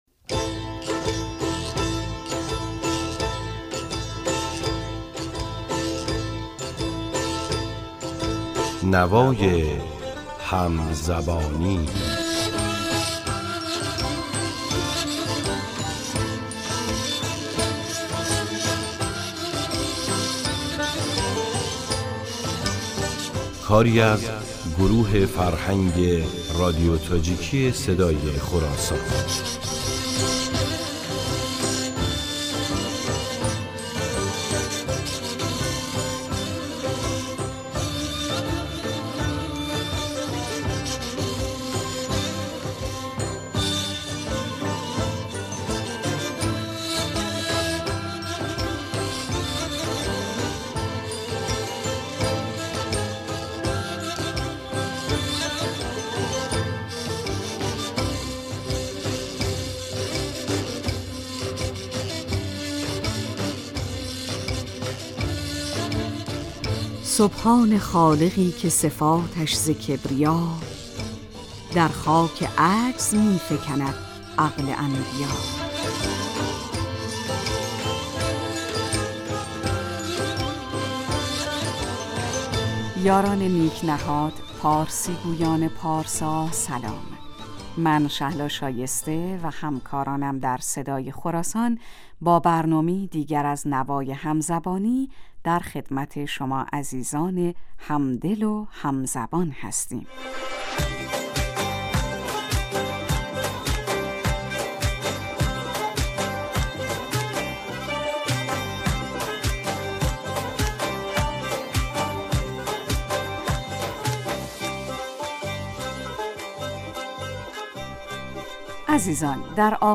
ساز و آواز